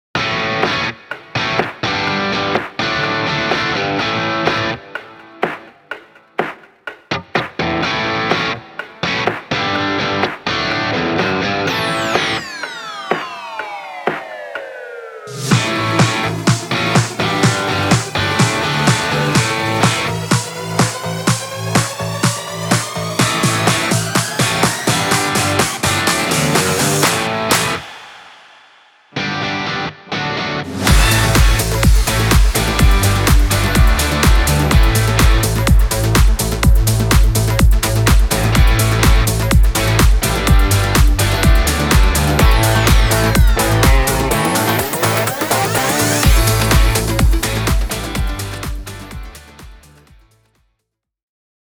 Коль уж мы в конец зафлудили тему и увели ее в дебаты, то кину набросок на один из треков. Задача - сделать фьюжн, условно, рока и дэнса, но не уходить в какой-то хардкор, брейкбит и прочие, уже хорошо представленные на рынке, штуки, а остаться в рамках uplifting коммерческой музыки.